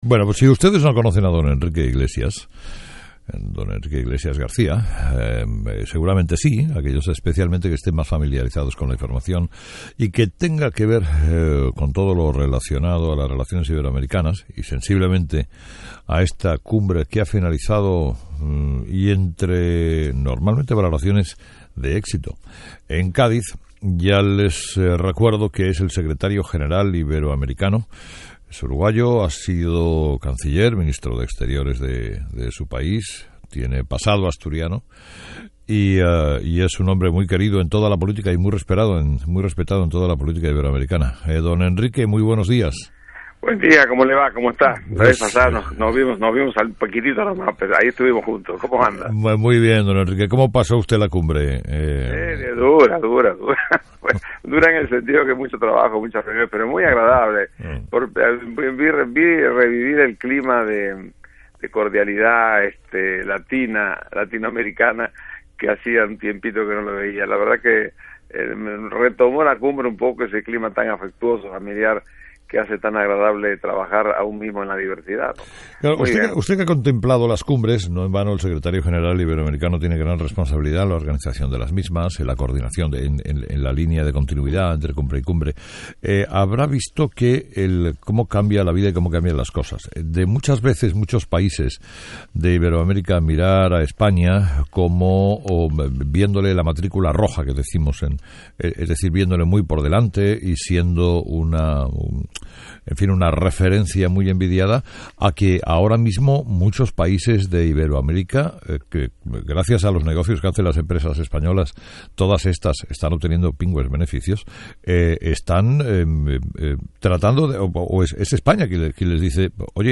Entrevista con Enrique Iglesias